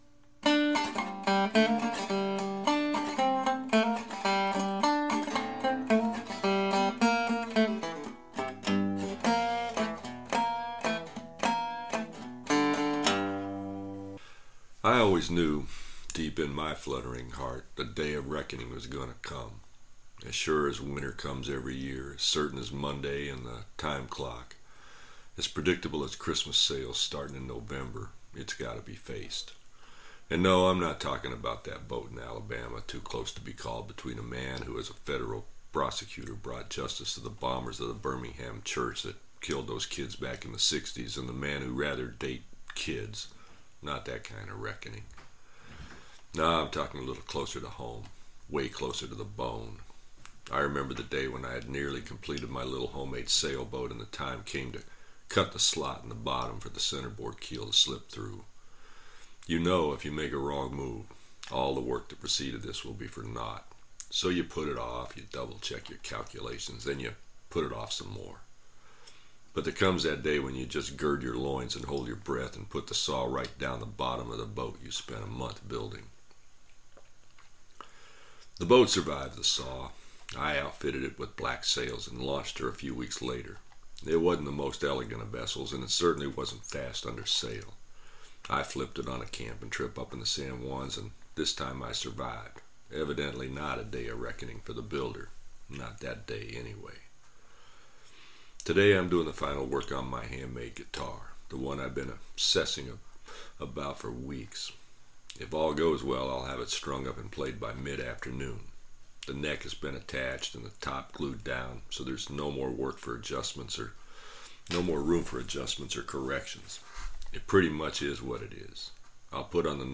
what that new guitar sounds like
audio-day-of-reckoning-w-new-guitar.mp3